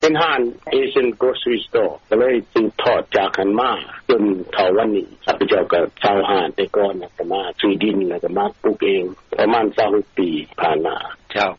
ທີ່ທ່ານຫາກໍໄດ້ຮັບຟັງຜ່ານໄປນັ້ນ ແມ່ນການໂອ້ລົມກັບລາວອາເມຣິກັນຄອບຄົວນຶ່ງ ໃນເຂດເມືອງໂທເລໂດ ລັດໂອຮາຍໂອ.